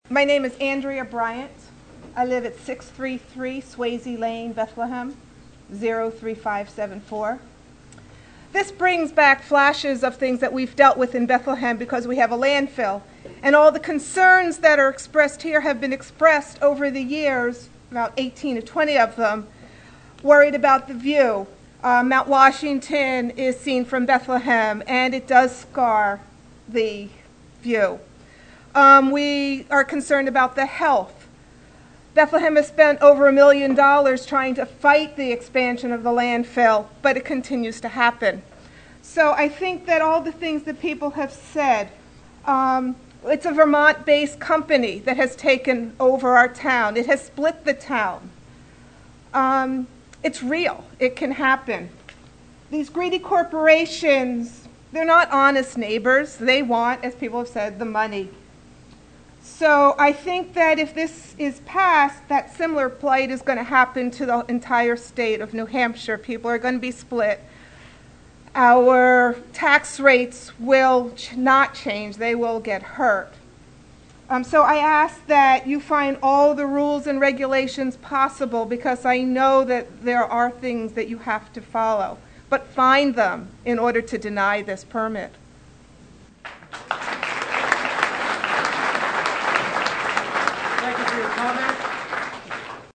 Public Scoping Meeting- Haverhill 3/20/11: